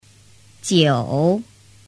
Jiǔ Chiểu 9